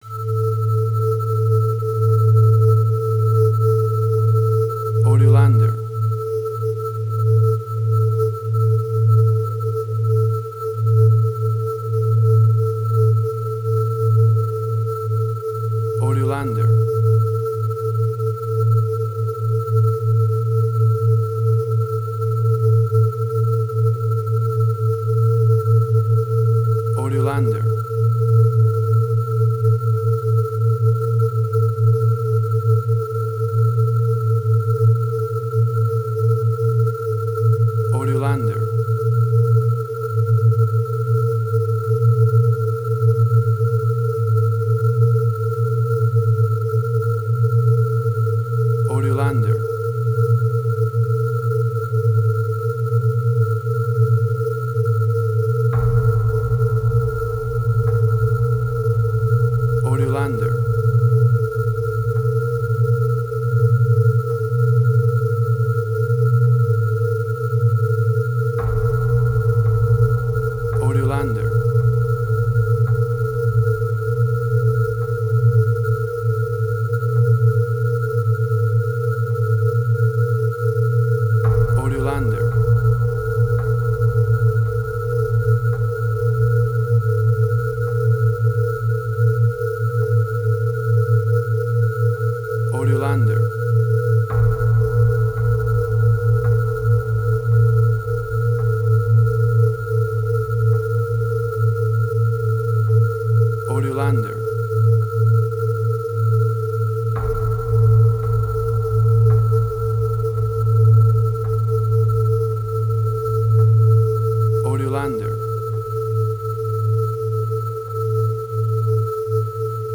Dissonance
emotional music
WAV Sample Rate: 16-Bit stereo, 44.1 kHz